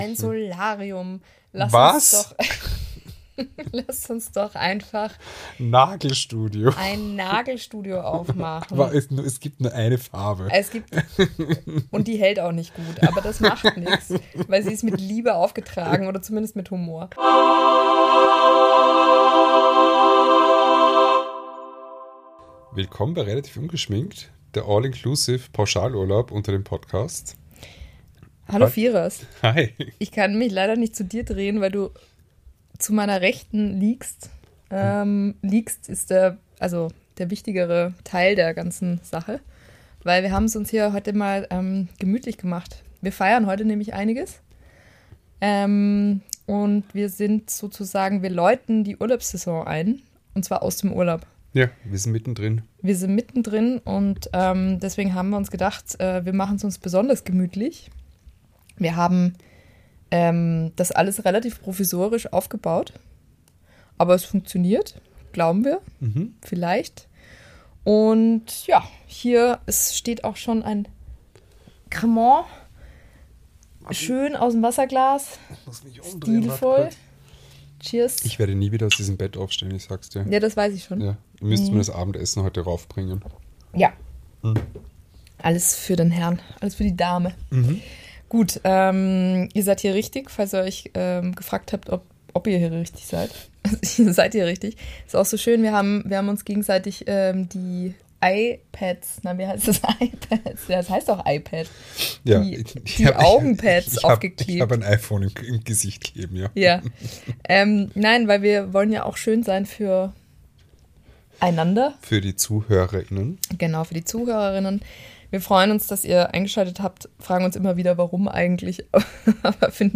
Die Redaktion meldet sich (erstaunlicherweise das erste Mal) aus der Vertikalen und verabschiedet sich damit in die Sommerfrische.